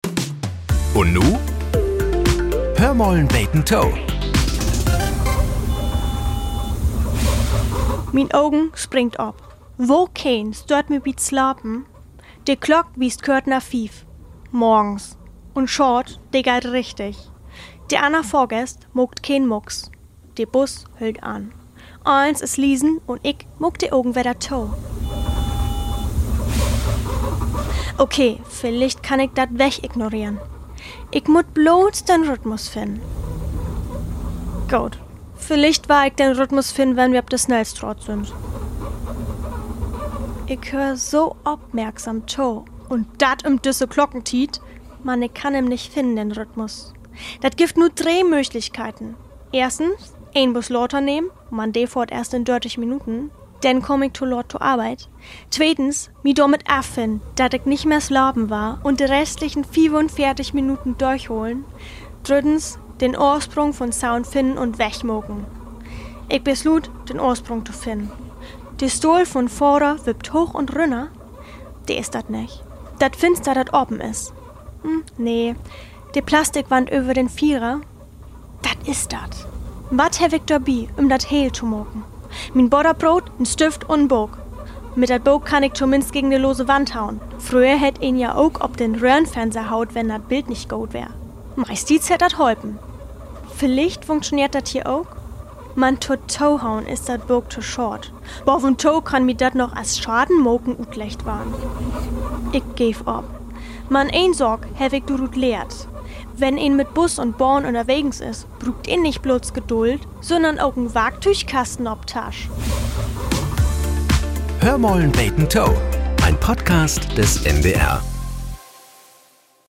Nachrichten - 20.05.2025